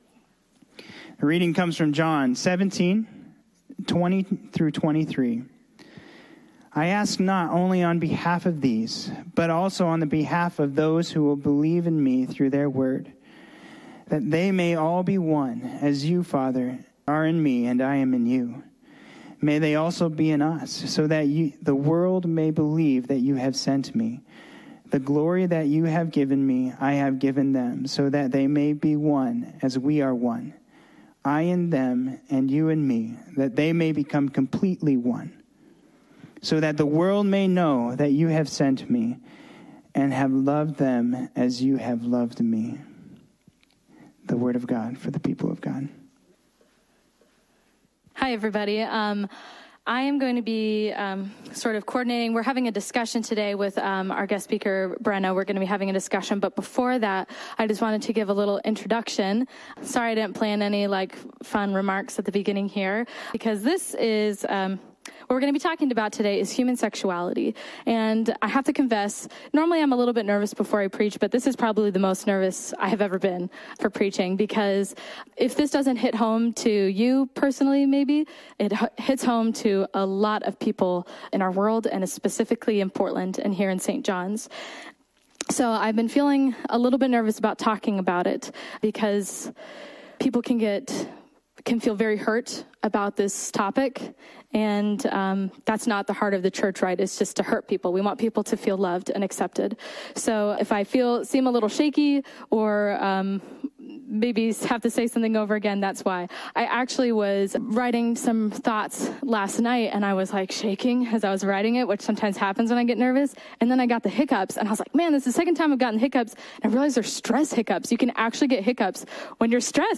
A Discussion on Human Sexuality